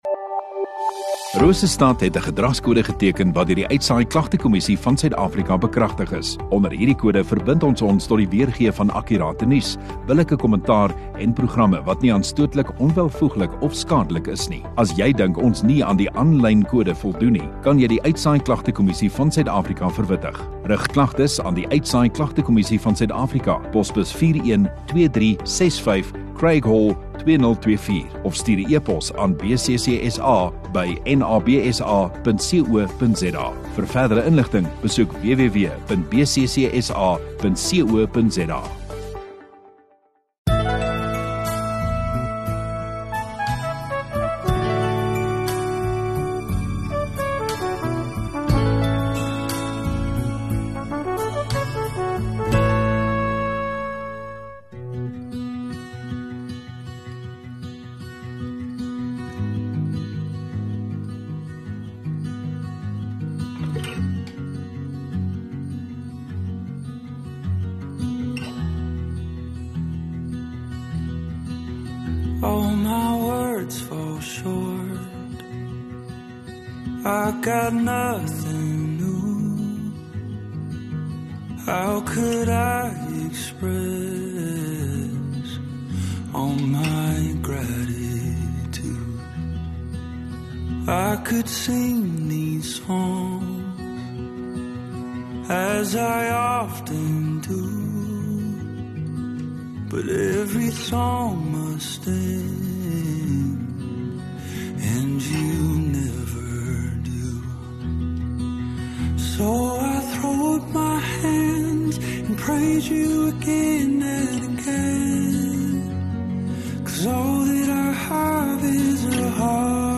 15 Feb Saterdag Oggenddiens